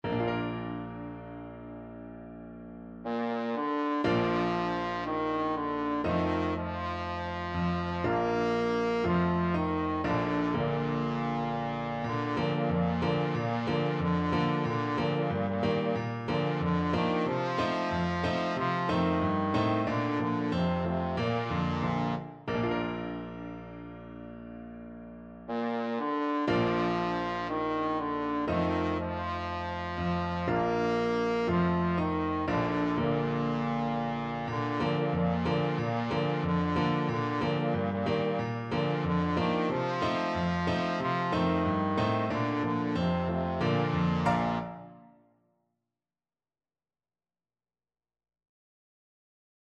Christmas Christmas Trombone Sheet Music Children, Go Where I Send Thee
Trombone
Bb major (Sounding Pitch) (View more Bb major Music for Trombone )
Slow =c.60
2/2 (View more 2/2 Music)
Traditional (View more Traditional Trombone Music)